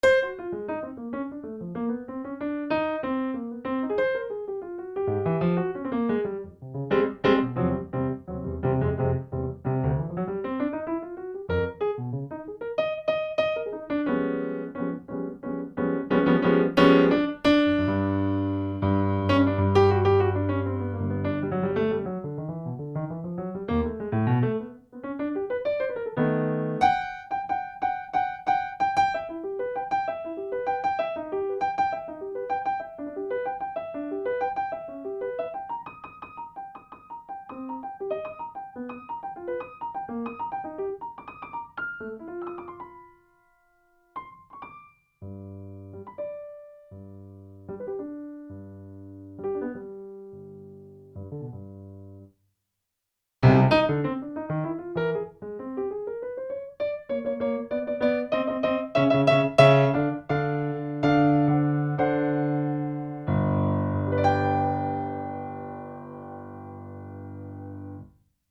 Some more melodic content this time, also some more dissonant and chromatic crazyness in this one.